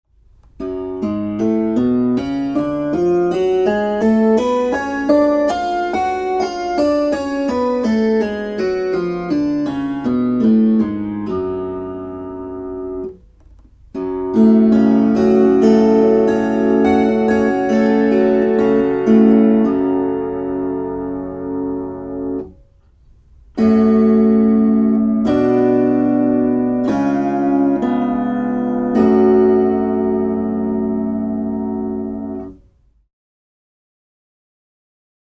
Kuuntele fis-molli. gis fis eis cis Opettele duurit C G D A E F B Es As mollit a e h cis d g c f Tästä pääset harjoittelun etusivulle.
fismolli.mp3